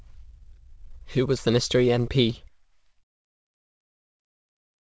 또한 음성에 대해서도 실험을 진행하였는데, VQ-VAE를 오직 long-term 상관관계(정보)만을 보존하도록 latent space를 만든 뒤 decoder로 복원하는 작업을 수행하였습니다. 예시를 들어보면 알 수 있겠지만, 그 내용은 변하지 않았으나 운율은 상당히 바뀐 것을 들을 수 있습니다.